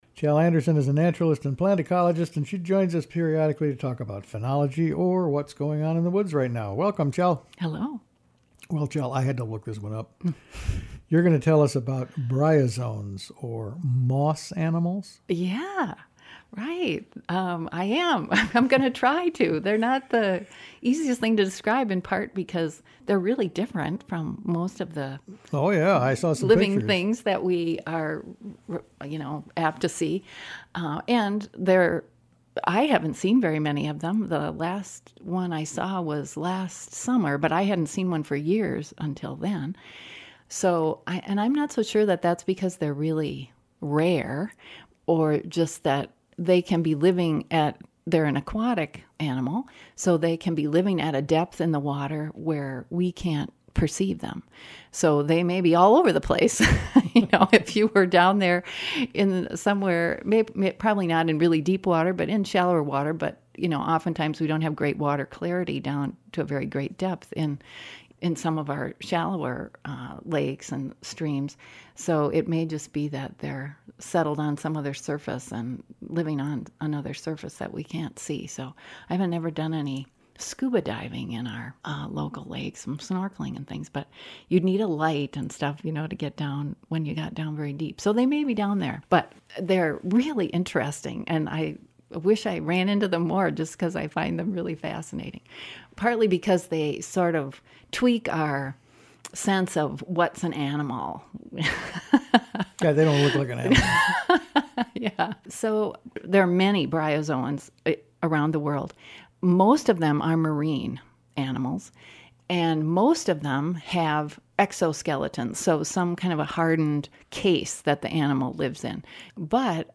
talks with naturalist